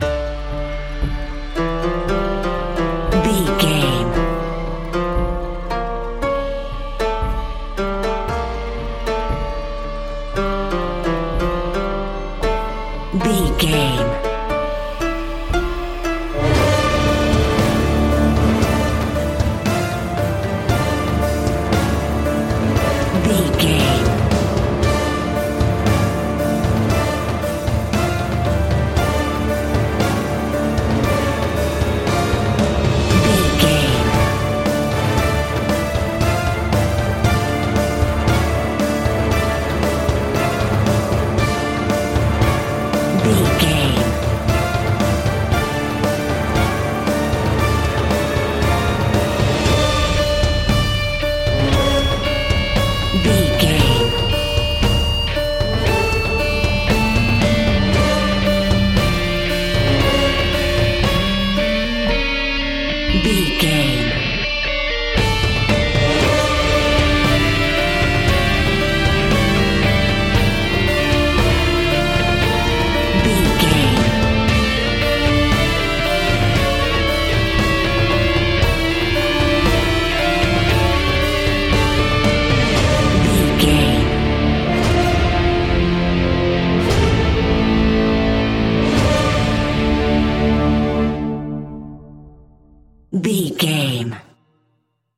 Big Dramatic Asian Action Music Score.
In-crescendo
Aeolian/Minor
Fast
tension
ominous
dark
dramatic
haunting
eerie
strings
brass
percussion
wood wind